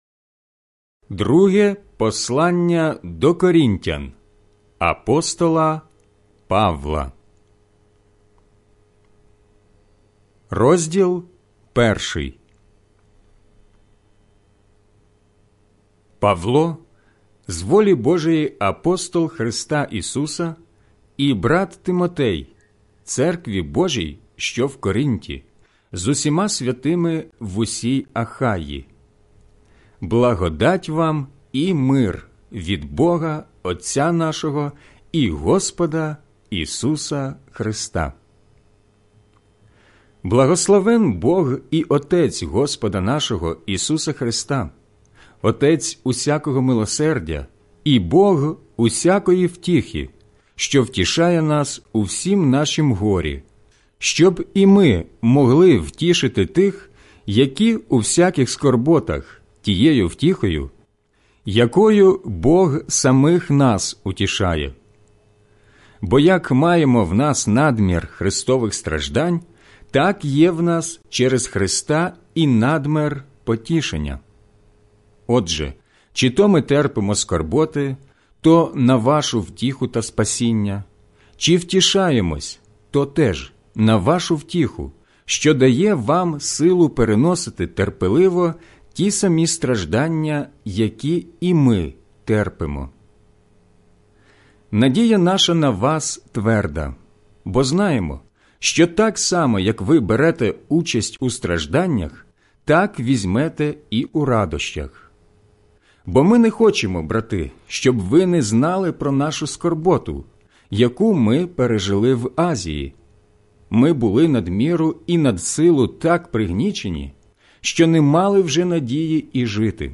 аудіобіблія